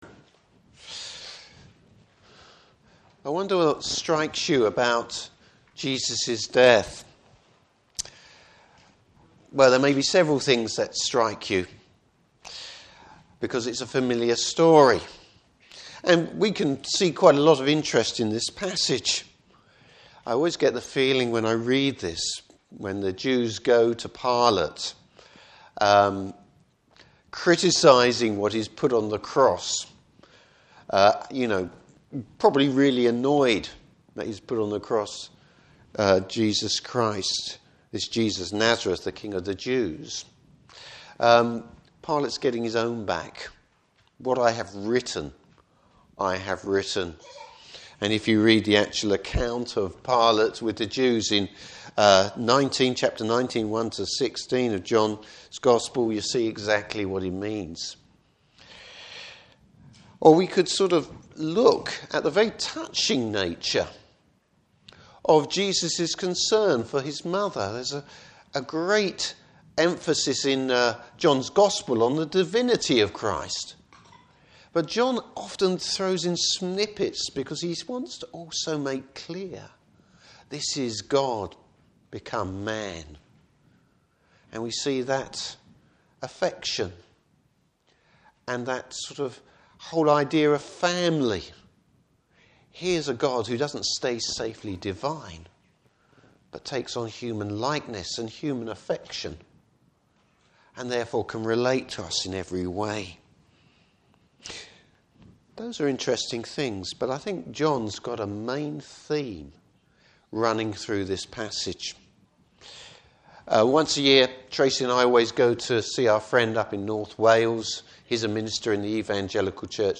Service Type: Good Friday Service.